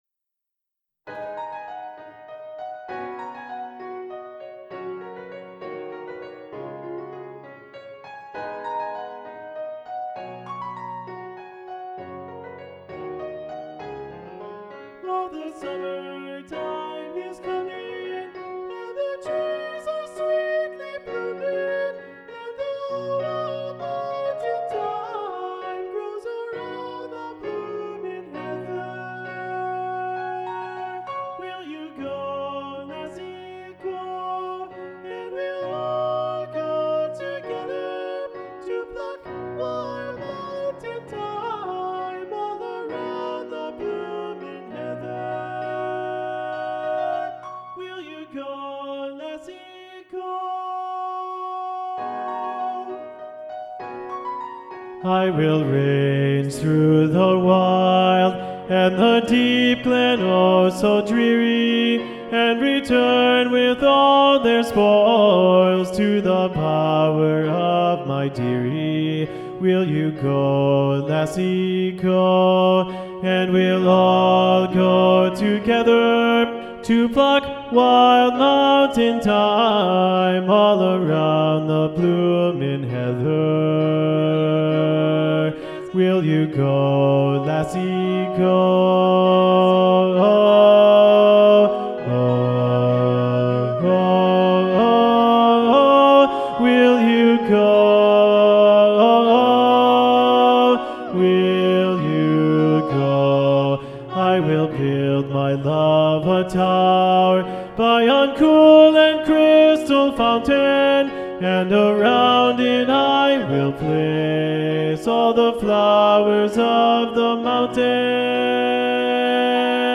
Bass 2